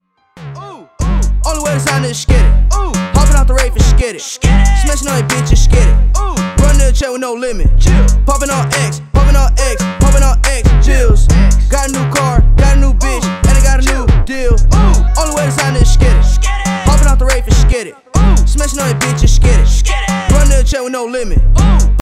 громкие
Хип-хоп
качающие
Bass